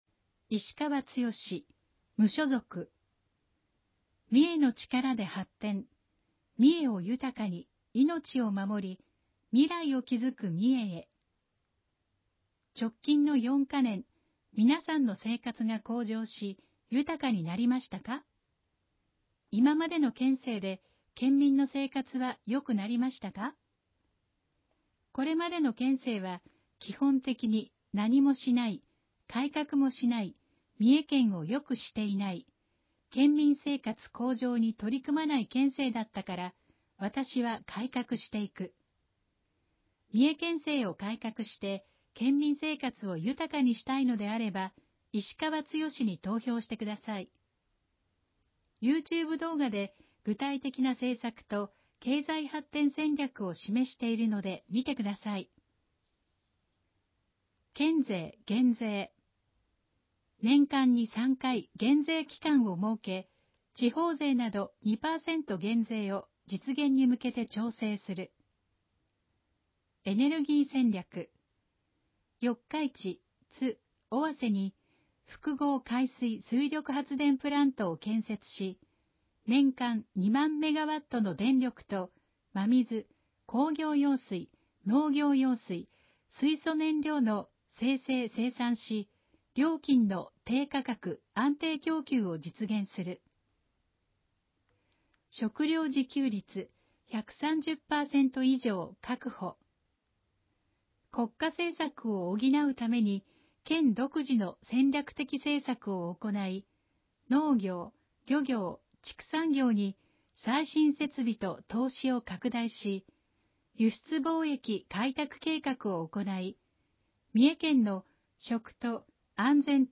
（選挙公報の音声読み上げ対応データは、現時点で提出のあった候補者のみ掲載しています）